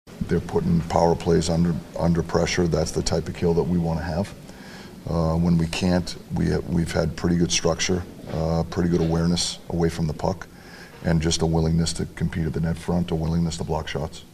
Coach Mike Sullivan says his PK team knows what it’s doing, with or without Pettersson.